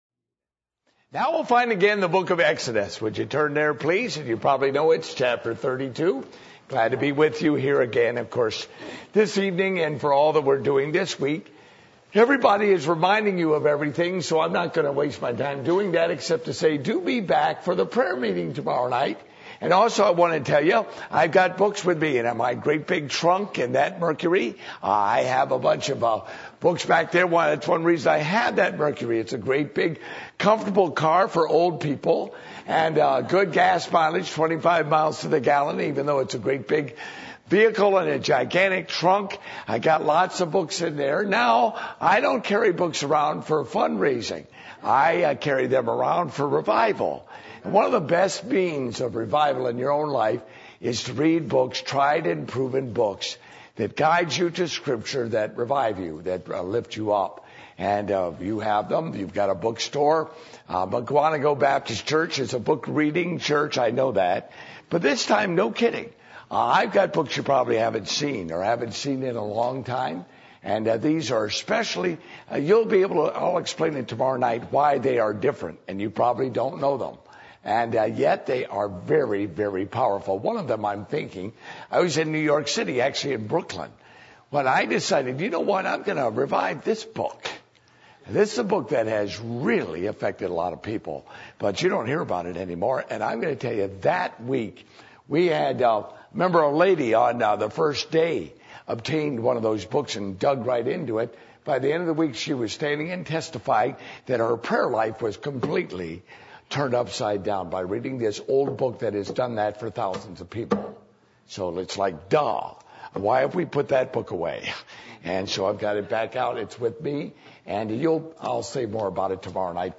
Passage: Exodus 32:1-10 Service Type: Revival Meetings